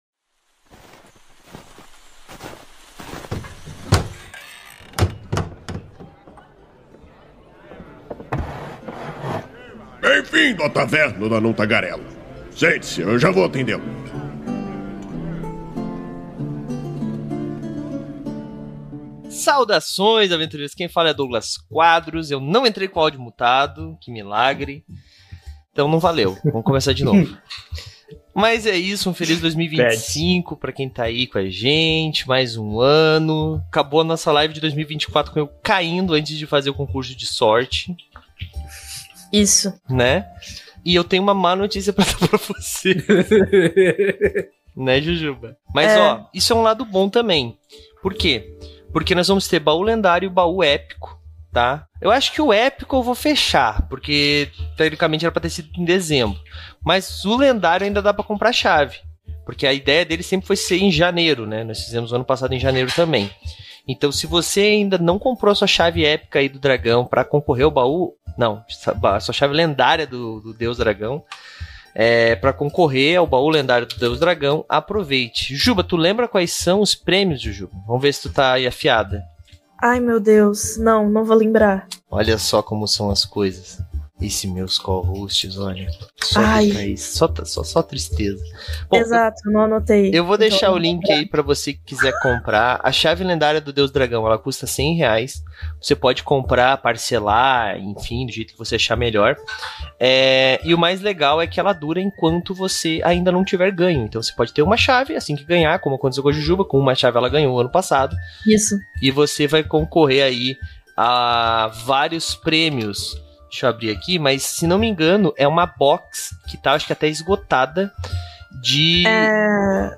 A Taverna do Anão Tagarela é uma iniciativa do site Movimento RPG, que vai ao ar ao vivo na Twitch toda a segunda-feira e posteriormente é convertida em Podcast. Com isso, pedimos que todos, inclusive vocês ouvintes, participem e nos mandem suas sugestões de temas para que por fim levemos ao ar em forma de debate.